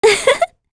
Talisha-Vox_Happy5_kr.wav